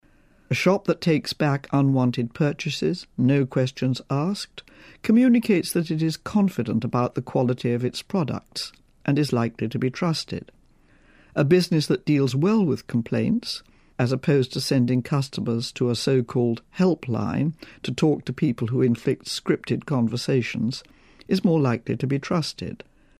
【英音模仿秀】惟诚可以破伪，惟实可以破虚 听力文件下载—在线英语听力室